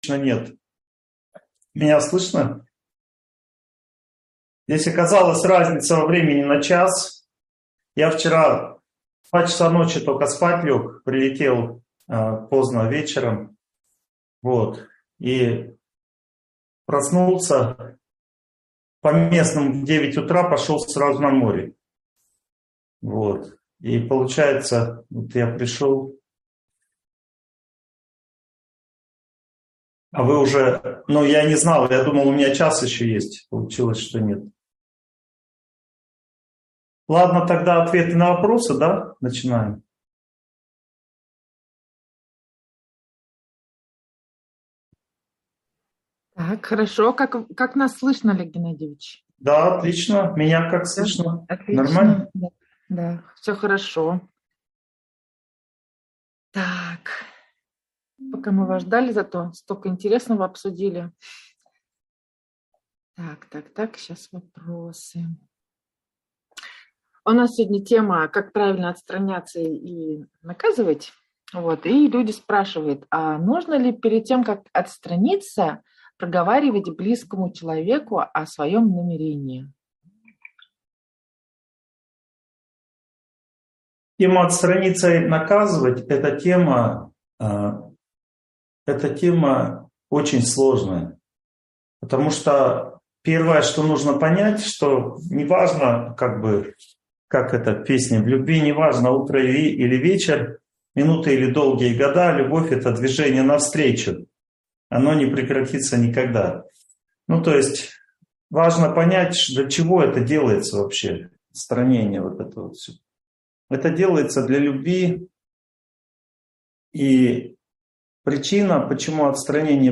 Как правильно отстраняться и наказывать (онлайн-семинар, 2023)